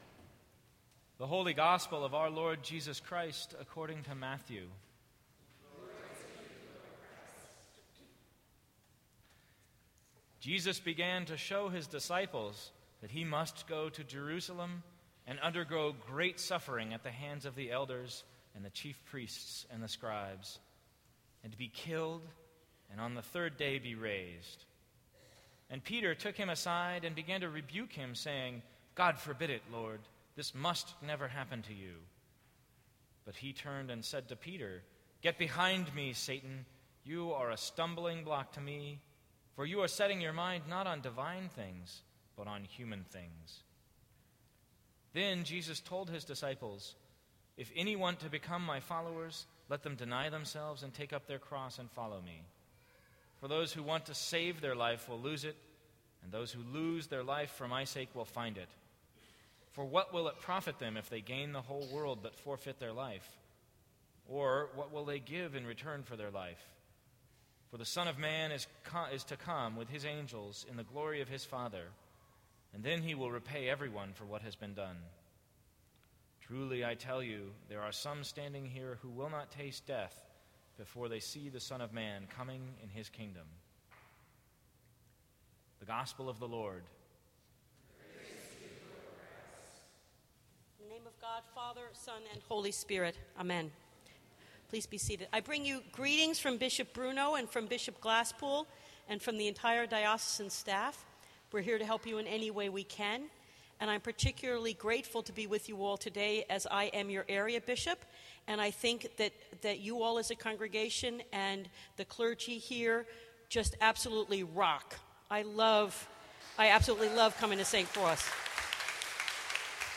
Sermons from St. Cross Episcopal Church August 31, 2014.